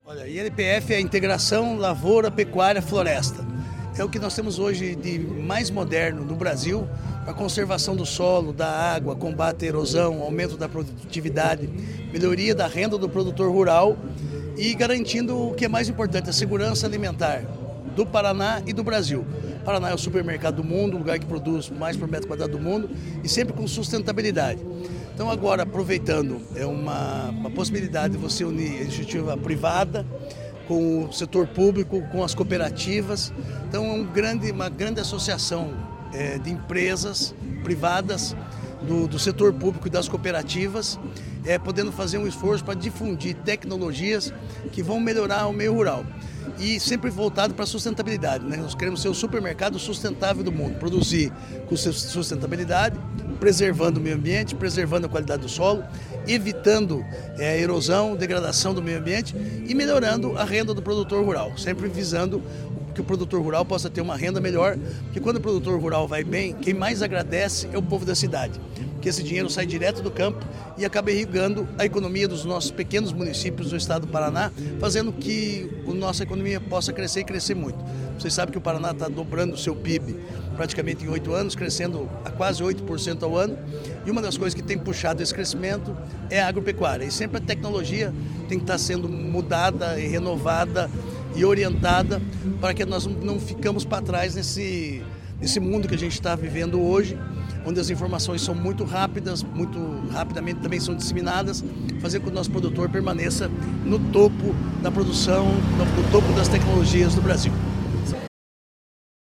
Sonora do secretário estadual da Agricultura e do Abastecimento, Marcio Nunes, sobre a ampliação do ILPF no Paraná e o fundo de investimentos FIDC Agro Paraná